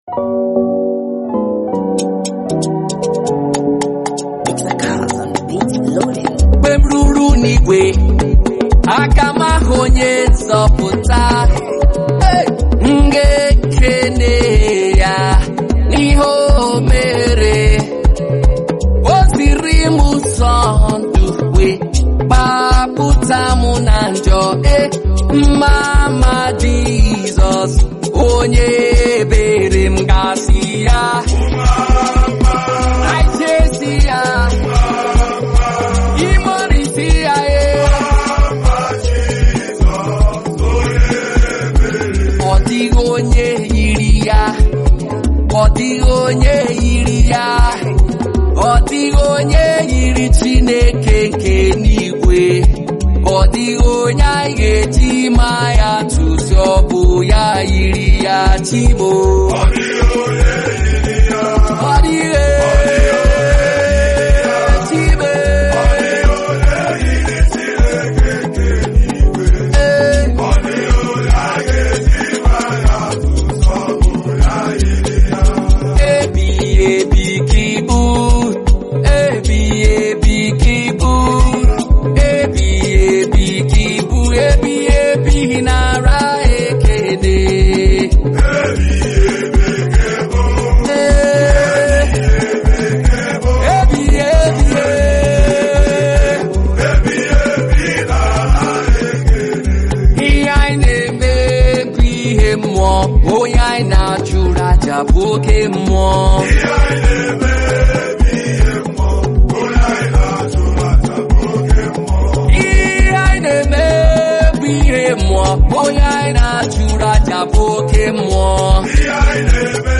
Traditional Music
Afrobeat Music